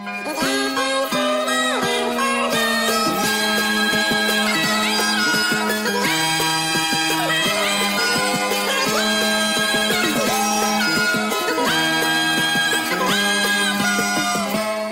Crying Duck Meme